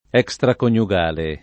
vai all'elenco alfabetico delle voci ingrandisci il carattere 100% rimpicciolisci il carattere stampa invia tramite posta elettronica codividi su Facebook extraconiugale [ H k S trakon L u g# le ] o estraconiugale agg.